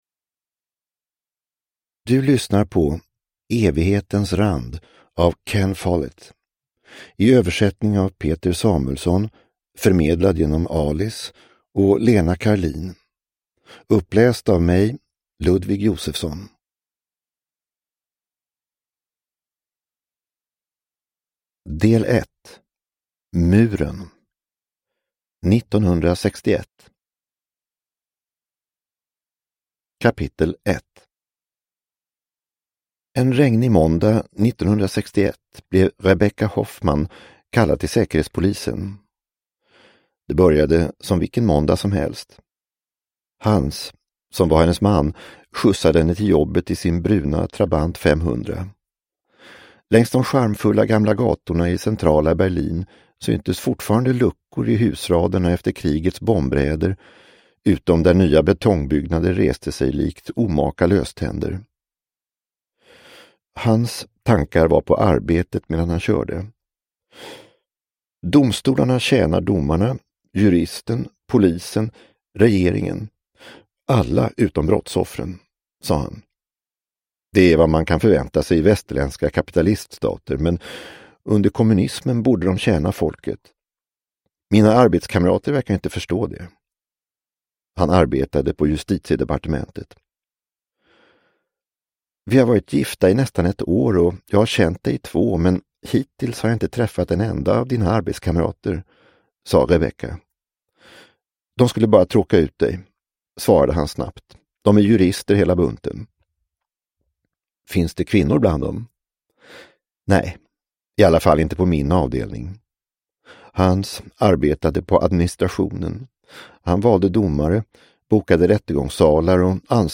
Evighetens rand – Ljudbok – Laddas ner